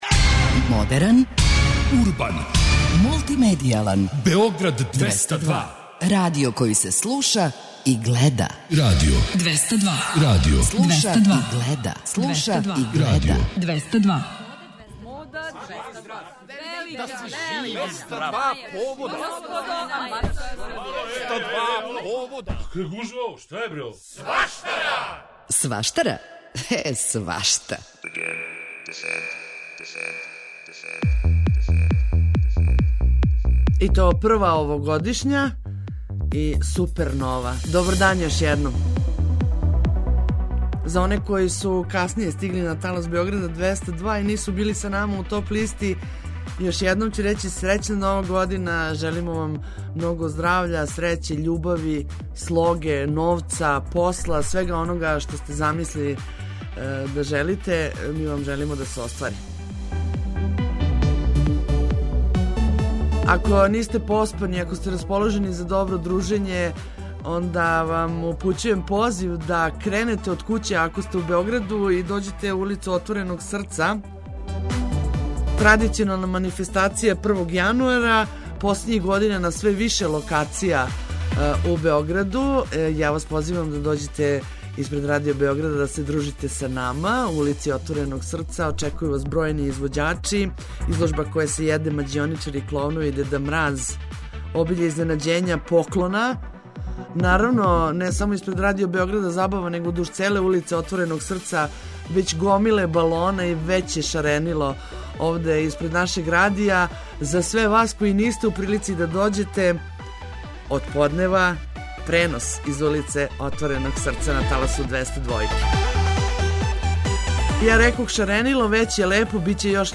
У првом овогодишњем издању „Сваштаре" емитујемо неке од најбољих прилога емитованих у претходној години.